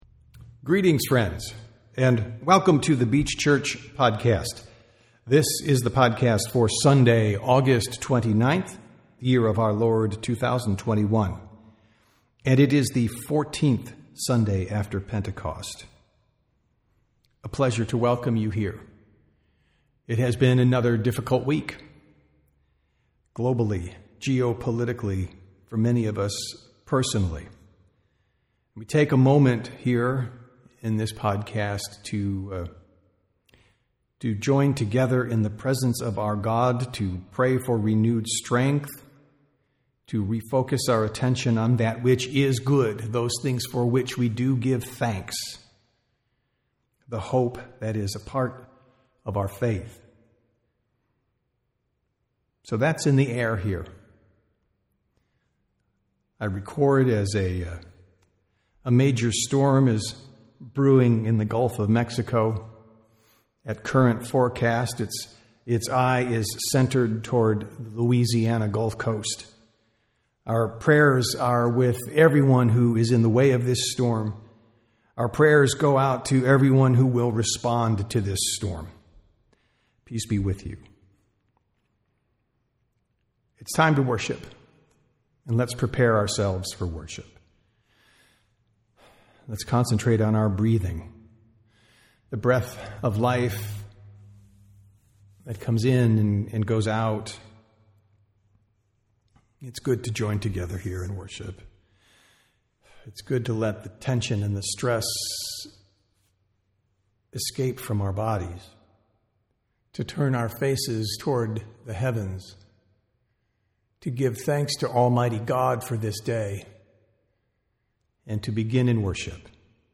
Sermons | The Beach Church
Sunday Worship - August 25, 2024